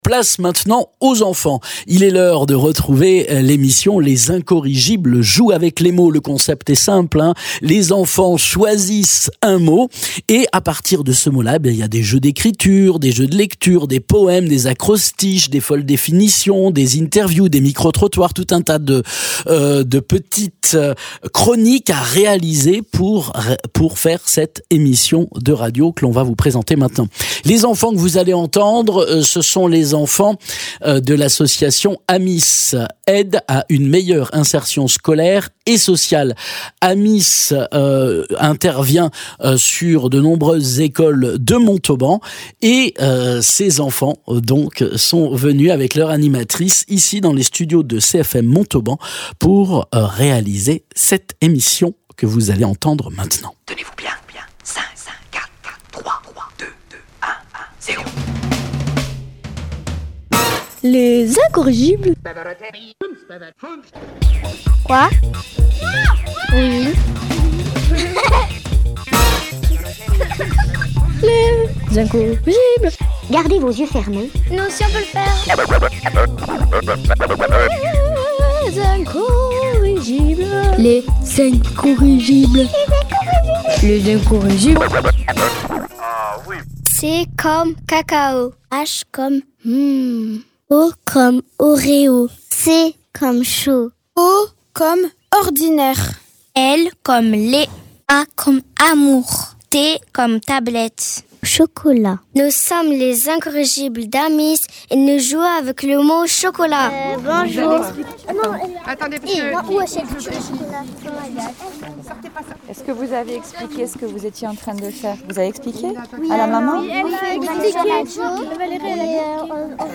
Les enfants de l’AMISS ont eu le plaisir de participer à l’enregistrement d’une émission sur CFM Radio, dans le cadre du projet Les Incorrigibles jouent avec les mots. Autour d’un mot aussi gourmand qu’inspirant — le chocolat — les enfants ont laissé libre cours à leur imagination à travers des virgules sonores, un poème acrostiche et une définition folle. Un beau moment de créativité, de rires et de fierté !